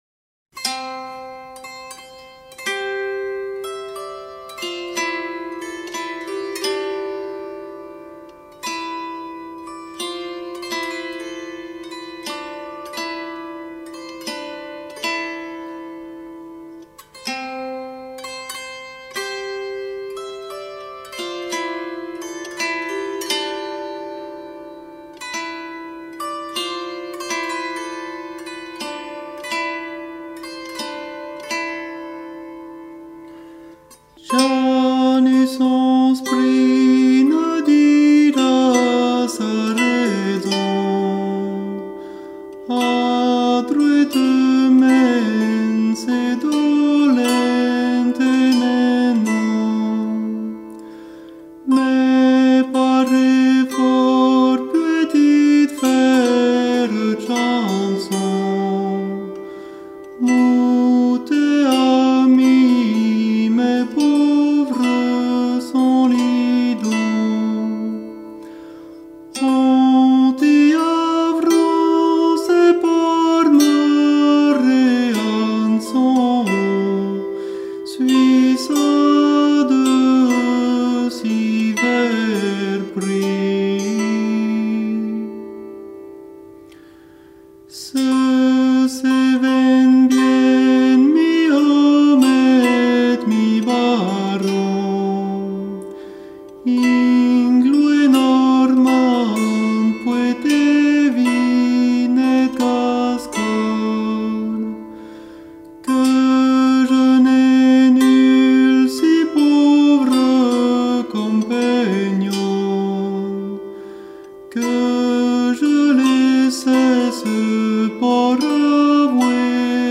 Aquesta és una de les poques músiques instrumentals de l’Edat Mitjana que ha arribat fins a nosaltres.
La poca música instrumental que ha arribat als nostres dies, és música de dansa.
Està escrita en un compàs que avui coneixem com a 6/8, en aquella època encara no sabien res de compassos, simplement es deixaven portar pel ritme de la dansa, i mai l’indicaven a la partitura.